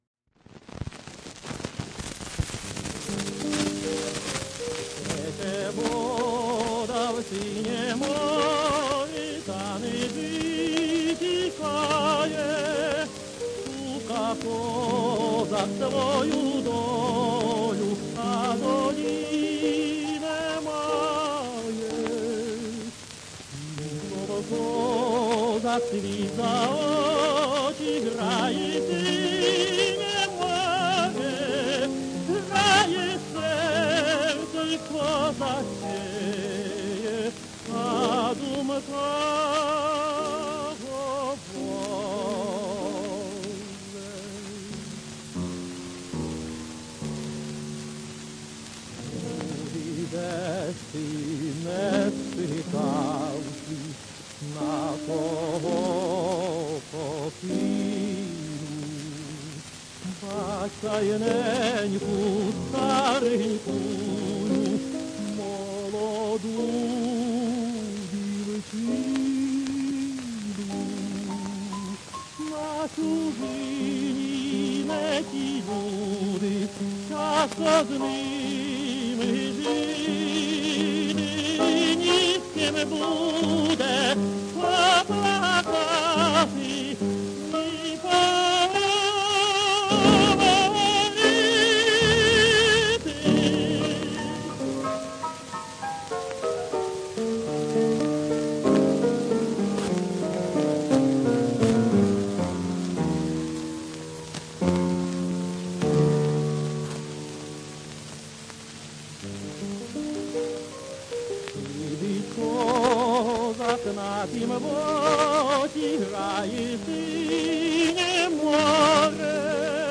украинские песни
Качество записи не очень хорошее, но послушаем: